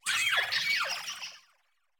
Cri de Triopikeau dans Pokémon Écarlate et Violet.